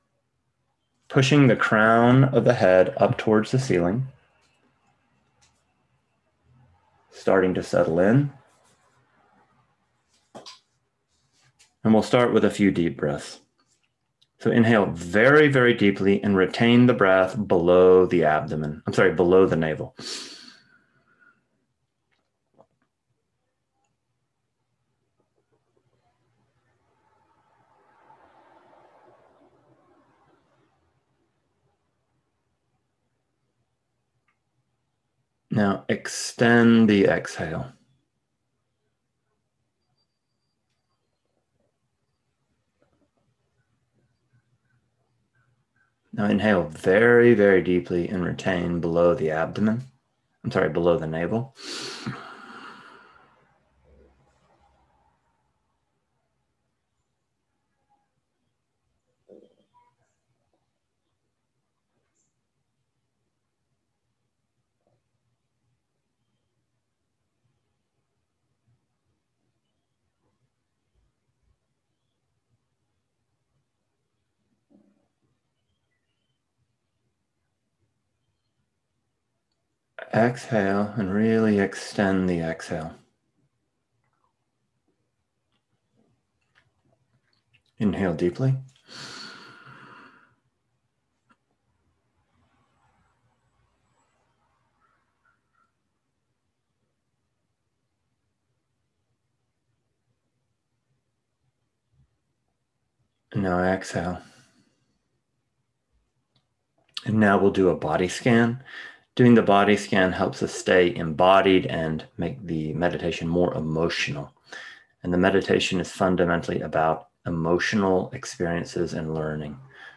Guided Meditation Only